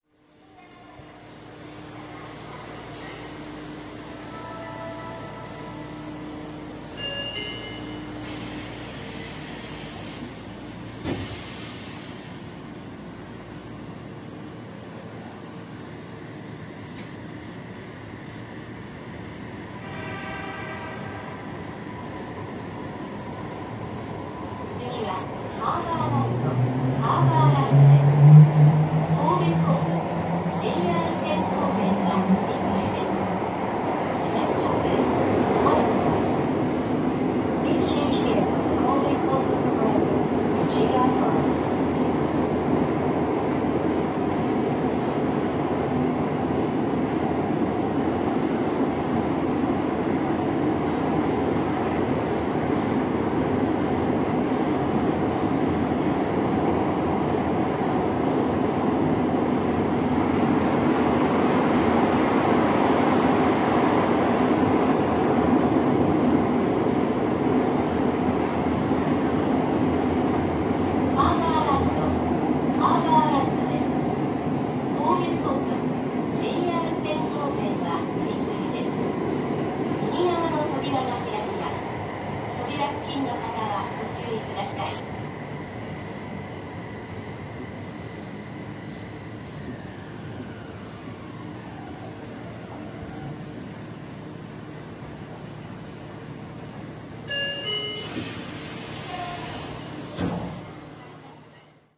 ついでに、神戸市交通局5000系の走行音を貼りつけておきます(これも未公開)
上記ファイルの収録は夏に行なっています。